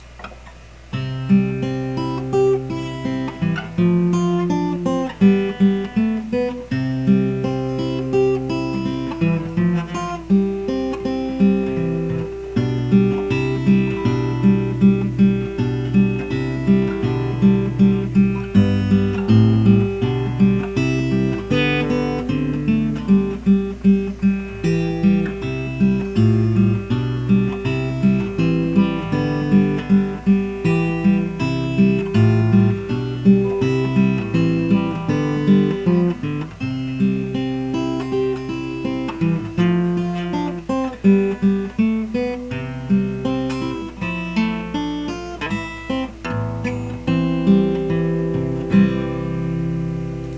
It's a little tricky, though, and the sound quality is very poor as a tradeoff for smaller files.
It would be to the advantage of all of you who like amateurish buzzy-sounding silly songs recorded on a little computer microphone, and to the detriment of my disk quota on the CS web server, but goodness, there's a lot of songs I could play.